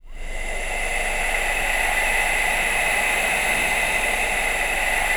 A-NOISEBED.wav